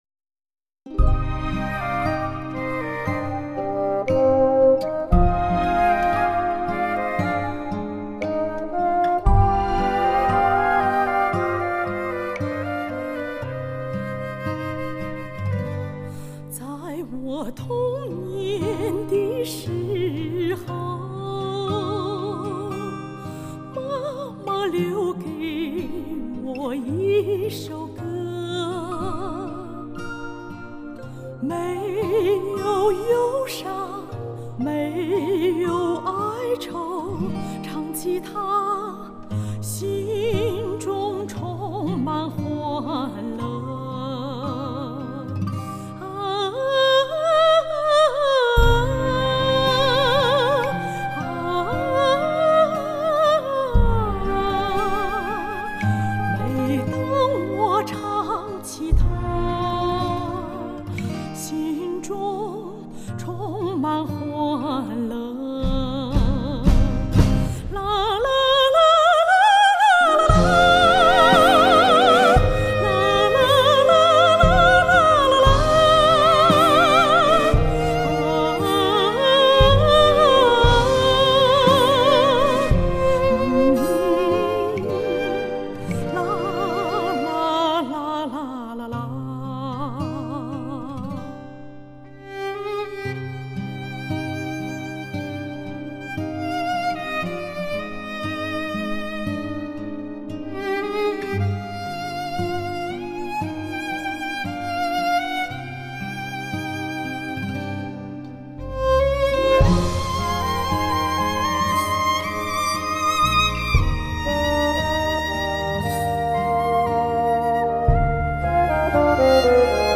插曲